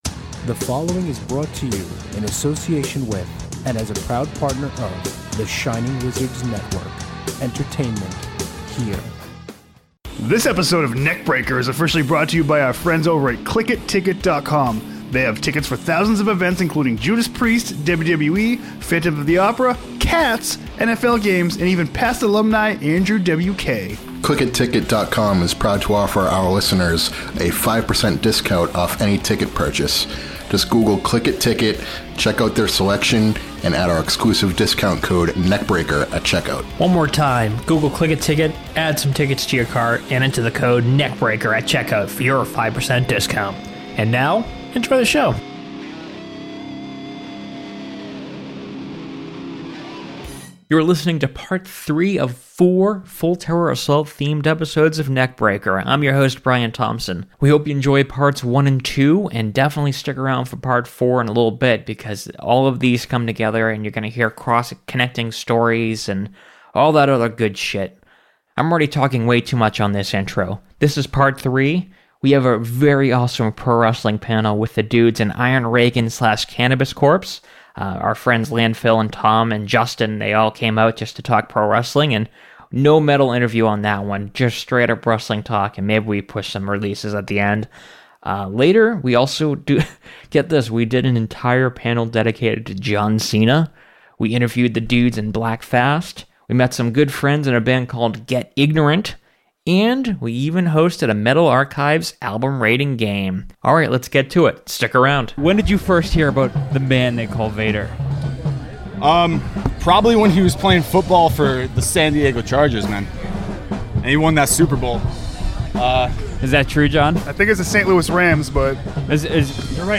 The third of the four episodes we recorded at Full Terror Assault fest with Cannabis Corpse / Iron Reagan! We talk old school pro wrestling, drink some beers, and much more.